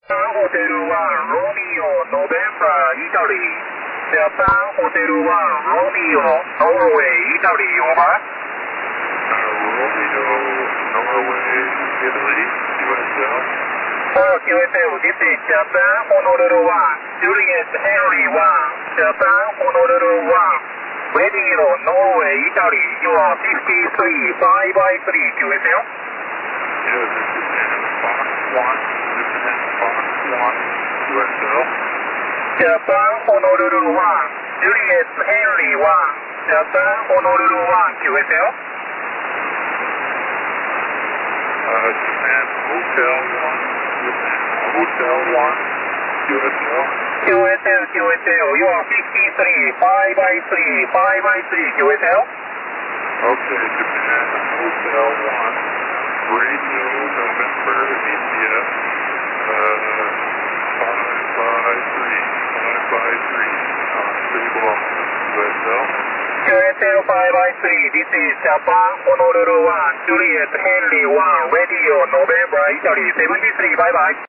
21MHz SSB